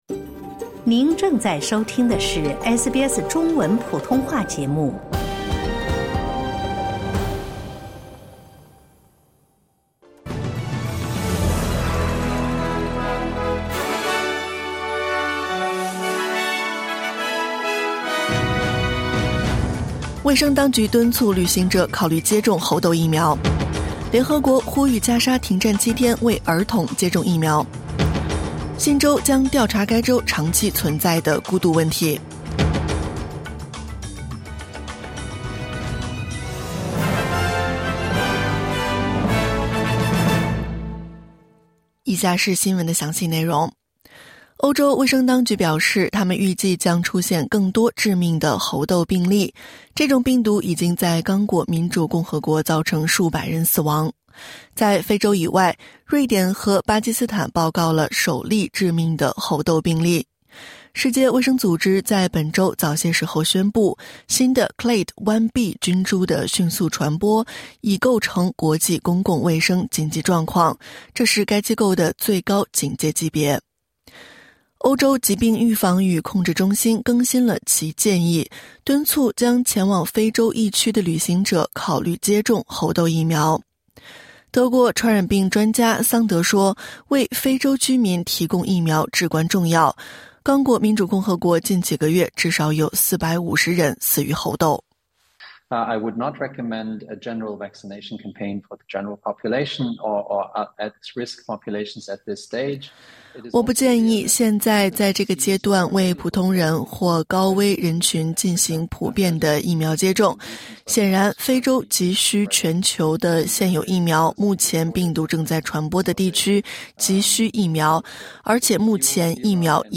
SBS Mandarin morning news Source: Getty / Getty Images